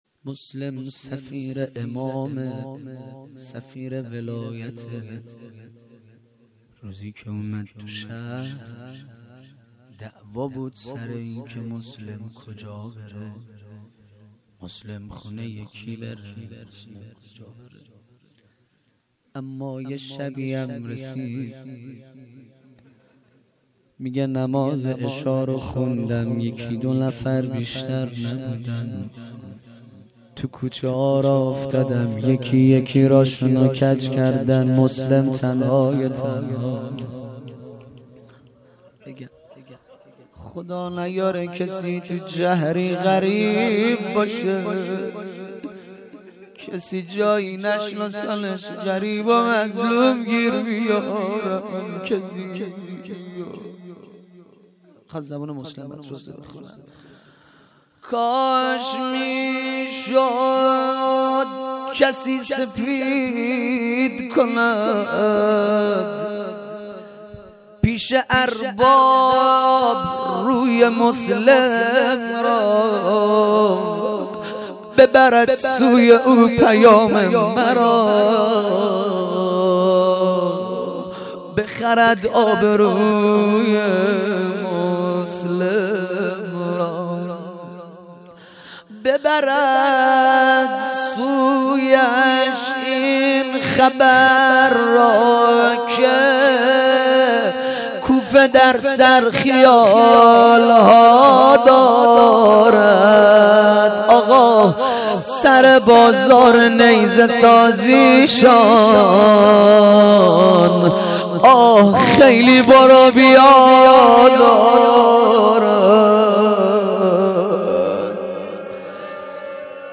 rozeh.mp3